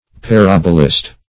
Parabolist \Pa*rab"o*list\ (-l[i^]st), n. A narrator of parables.